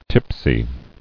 [tip·sy]